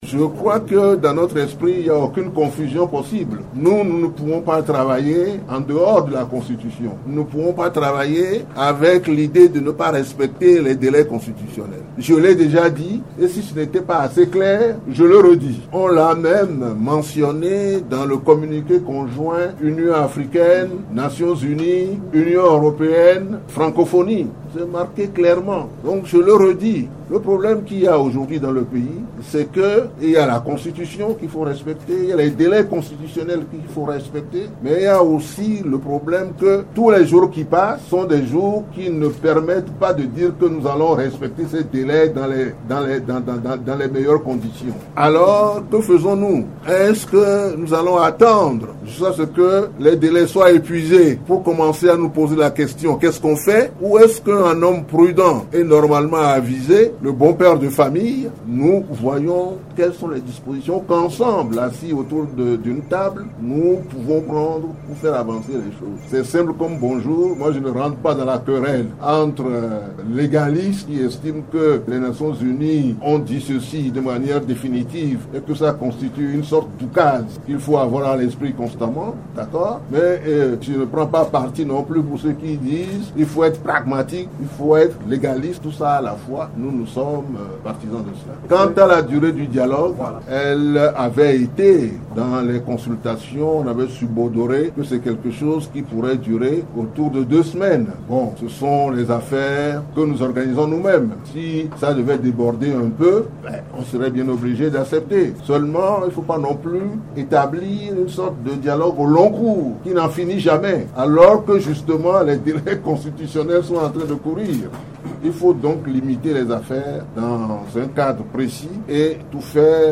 Déclaration d'Edem Kojo à la presse avec Top Congo FM